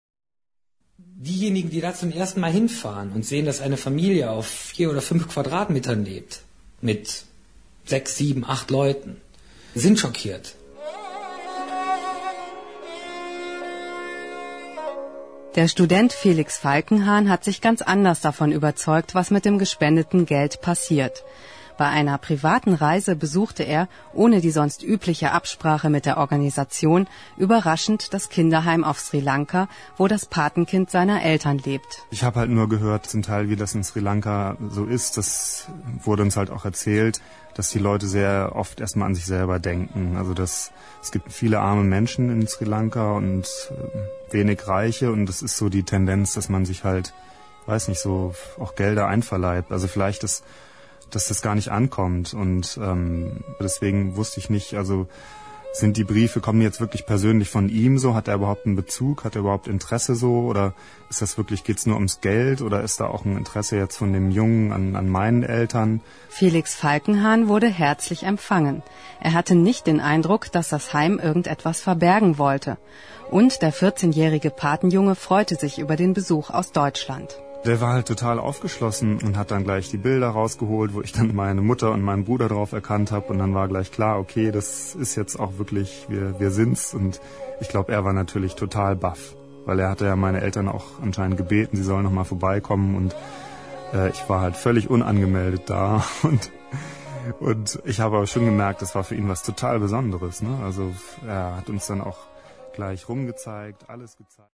Kinder staunen über ihre eigene Stimme.
Guatemala 2004